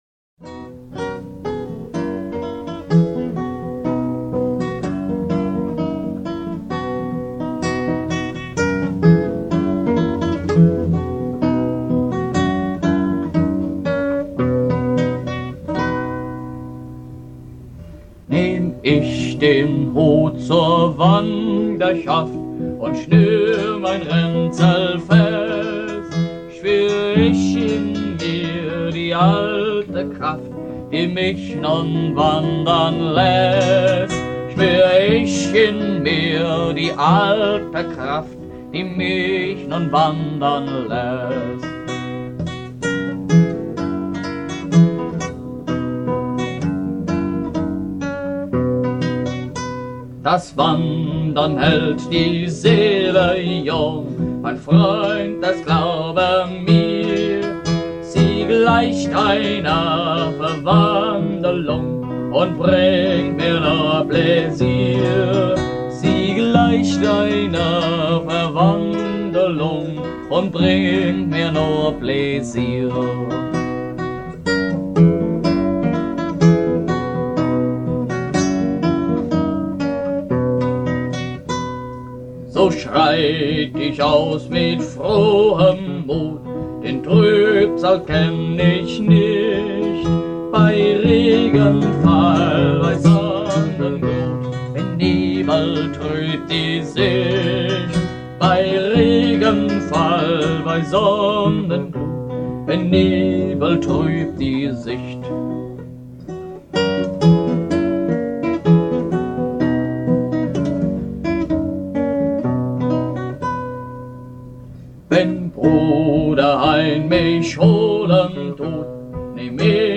Meine frühen Lieder